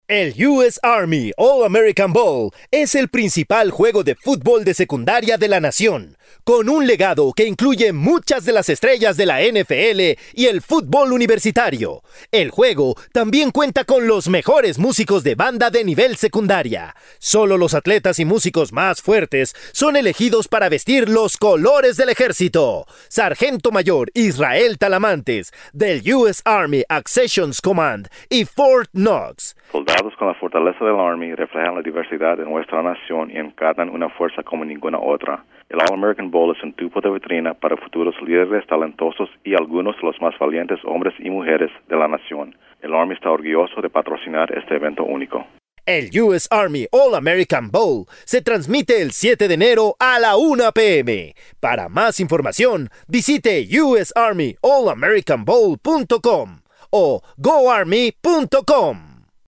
January 3, 2012Posted in: Audio News Release